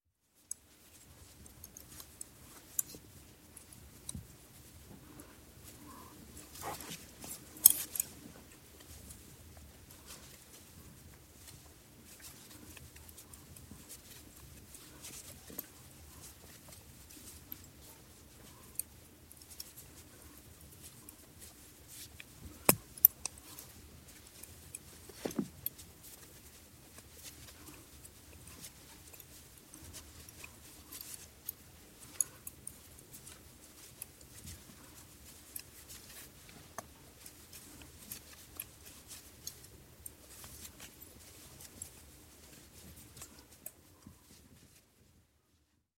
На этой странице собраны звуки вязания: мягкое постукивание спиц, шелест пряжи, ритмичные движения рук.
Звук вязания волка из Красной шапочки